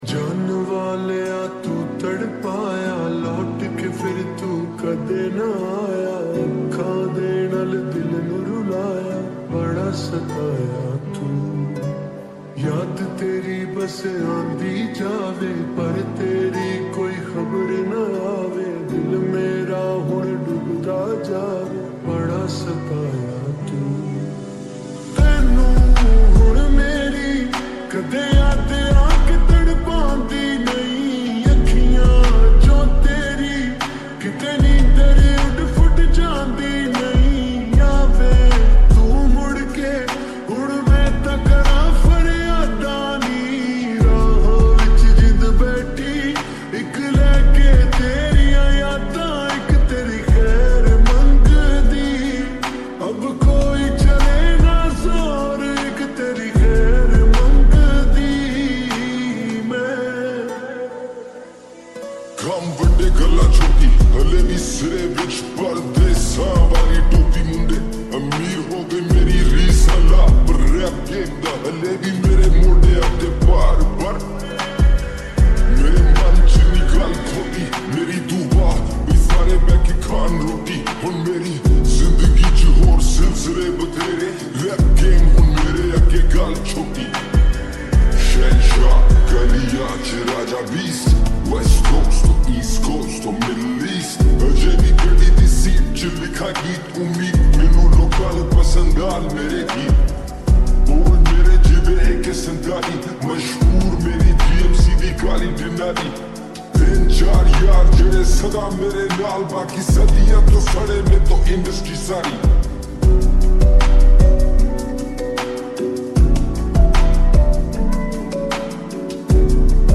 𝗠𝗮𝘀𝗵𝘂𝗽 🥺💞🫣🫀 (Slowed + Reverb)